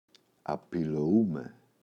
απηλοούμαι [apiλo’ume]